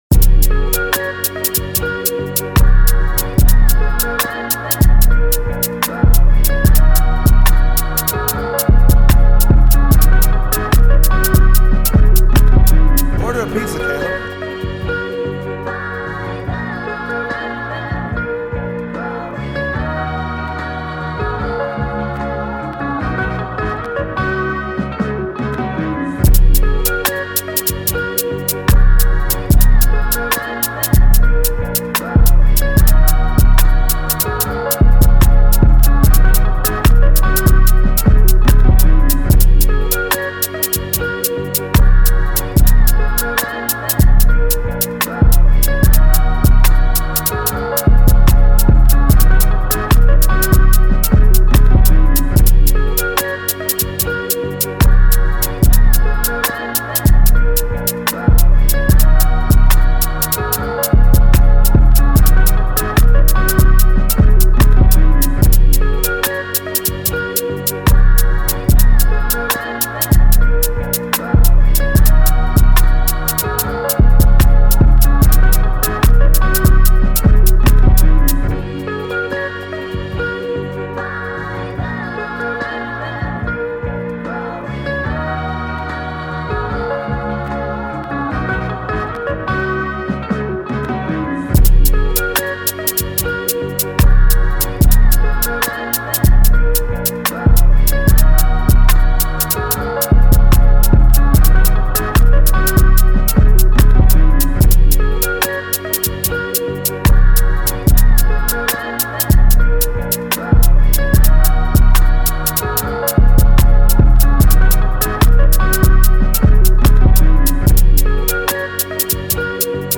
official instrumental